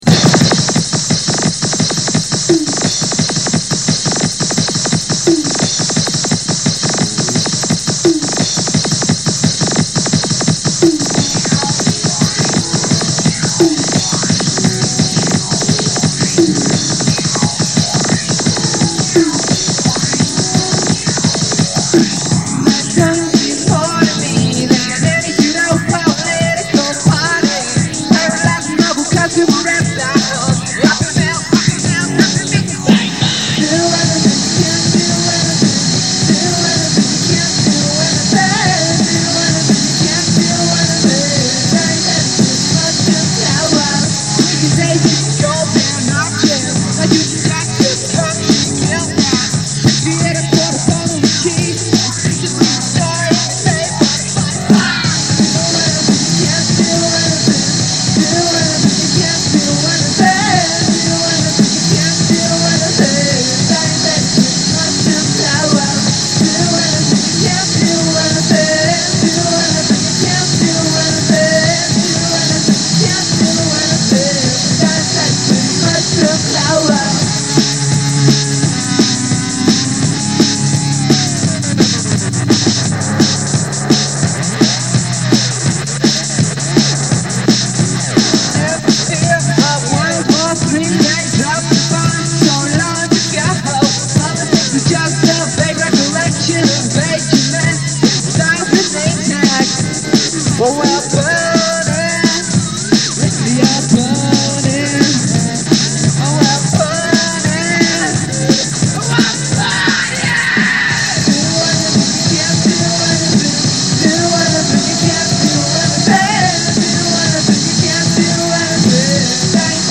Vocals, Bass, Engineering
Guitar, Loops, Engineering, Effects
Guitar, Noises
Synth Drums, Programmed Drums